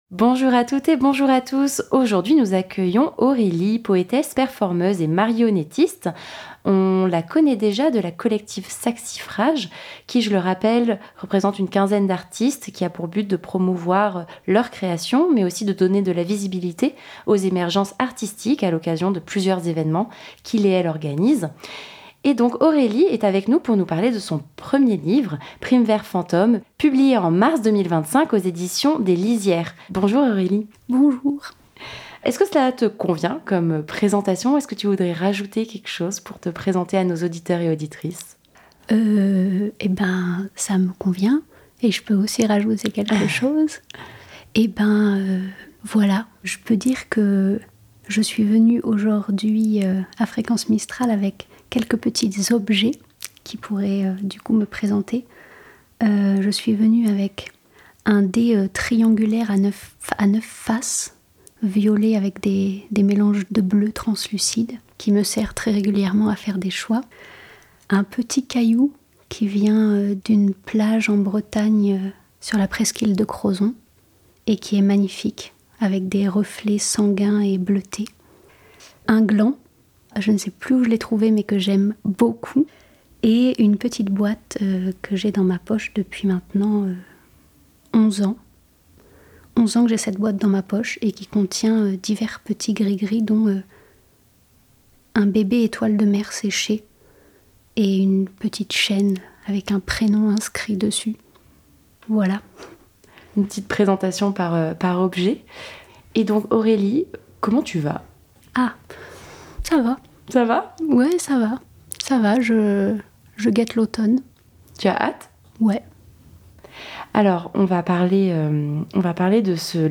un Mag rien que pour vous, des invité.e.s en direct